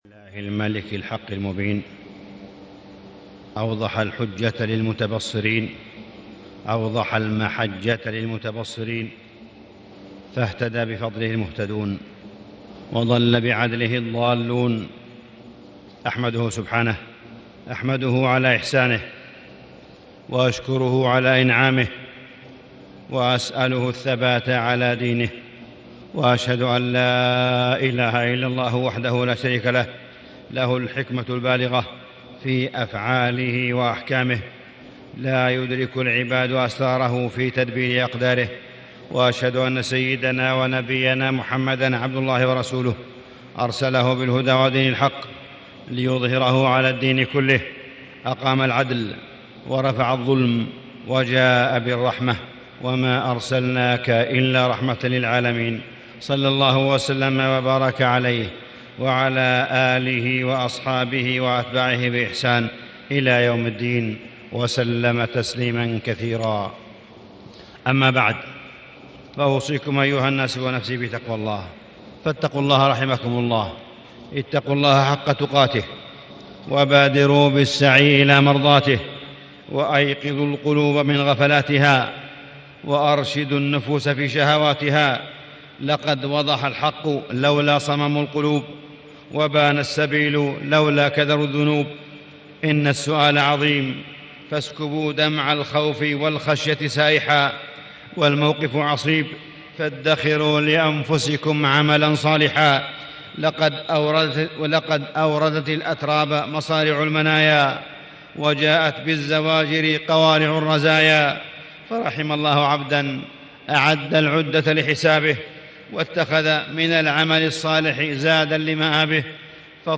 تاريخ النشر ٢٥ شعبان ١٤٣٦ هـ المكان: المسجد الحرام الشيخ: معالي الشيخ أ.د. صالح بن عبدالله بن حميد معالي الشيخ أ.د. صالح بن عبدالله بن حميد استقبال شهر رمضان وآلام الأمة